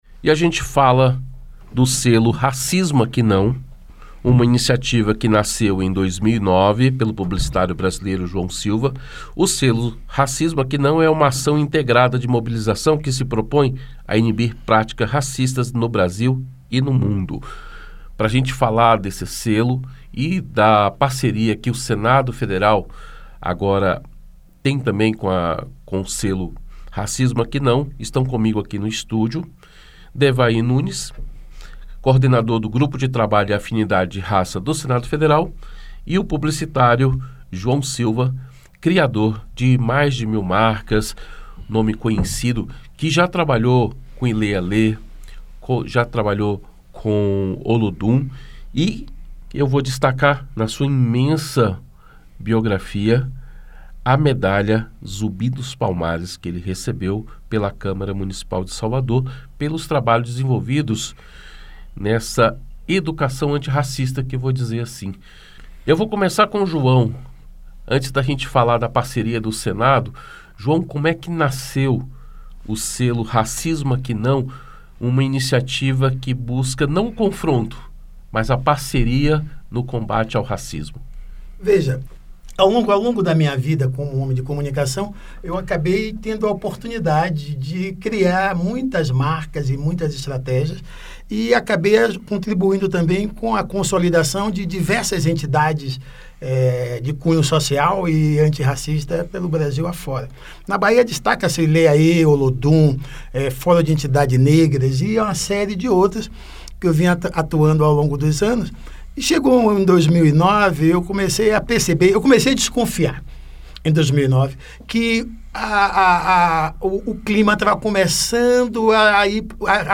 Entrevista: Senado adota selo de combate à discriminação racial